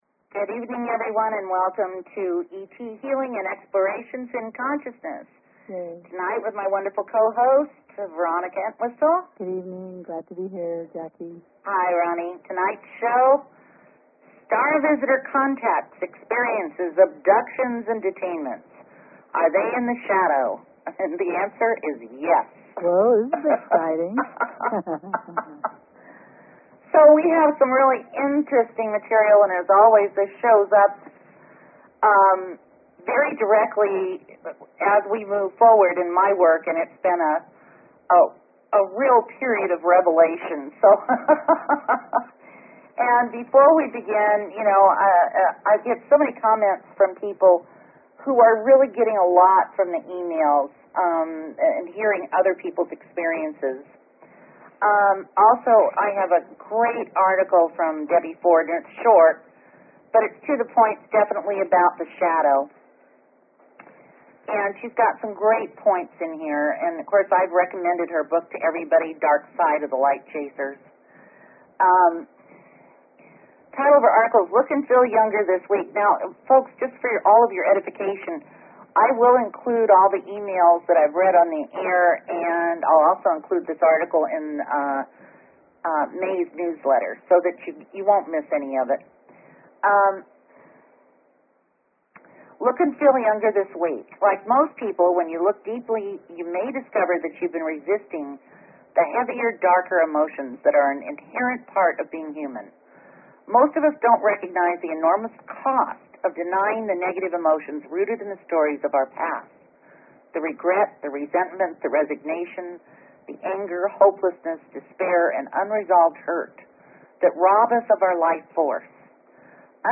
Talk Show Episode, Audio Podcast, ET_Healing and Courtesy of BBS Radio on , show guests , about , categorized as